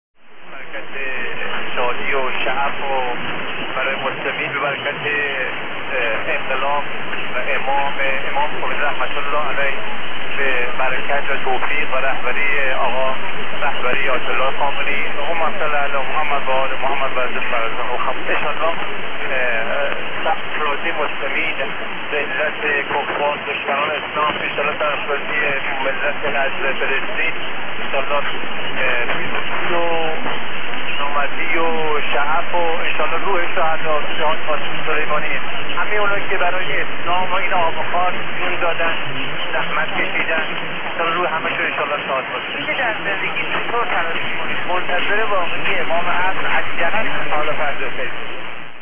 There again with Farsi talk from Feb 2024.